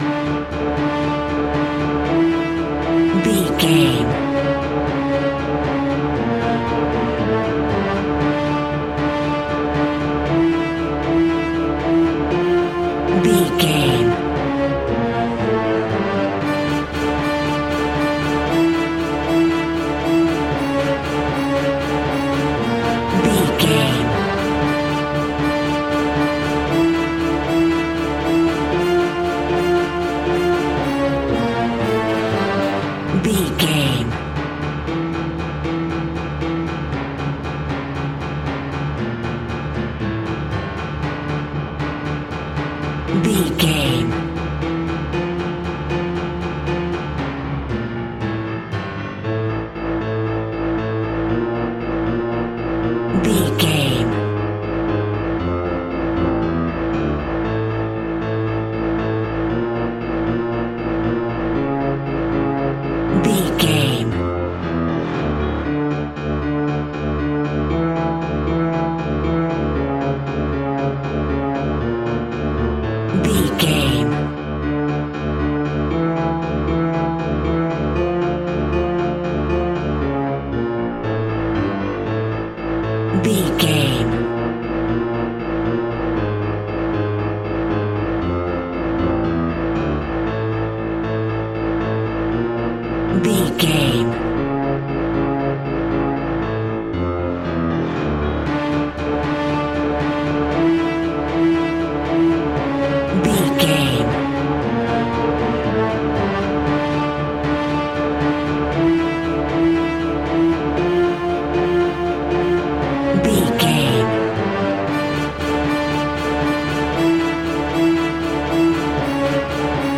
Brass Thriller.
Aeolian/Minor
Fast
ominous
suspense
dramatic
orchestra
strings
percussion
piano